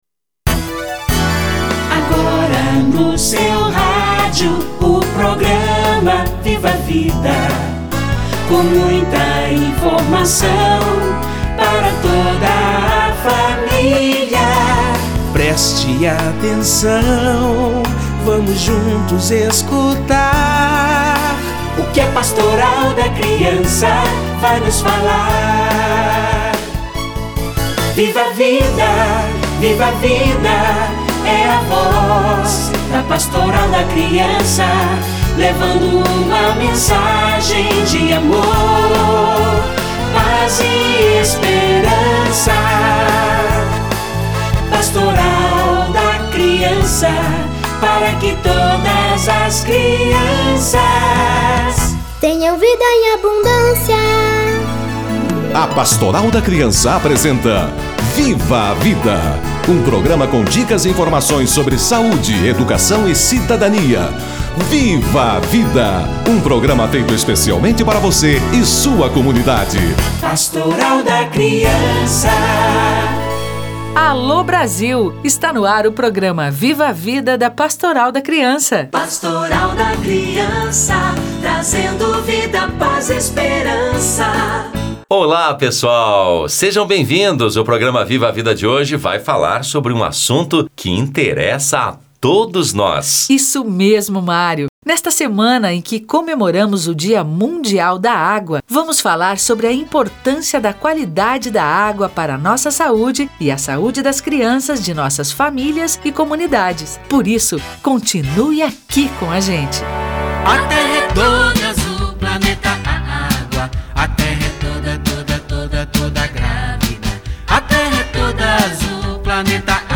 Água - Entrevista